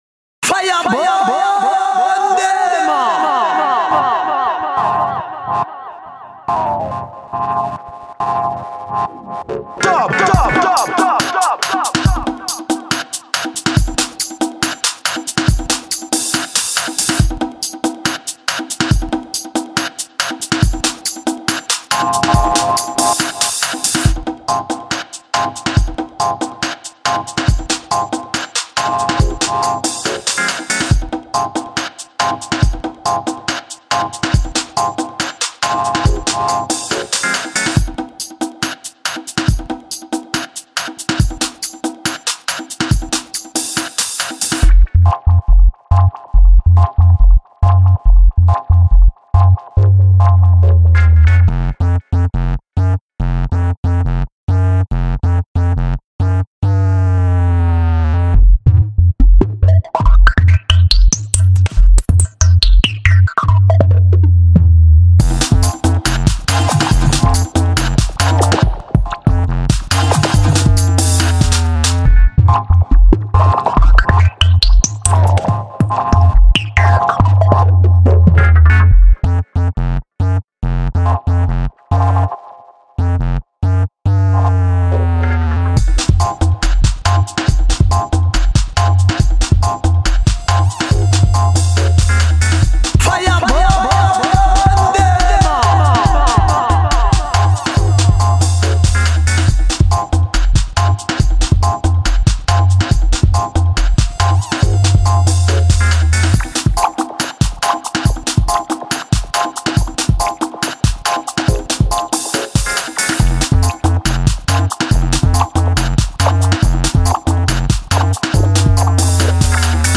Experimental Dub